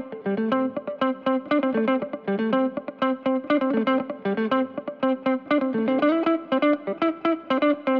31 Guitar PT2.wav